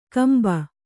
♪ kamba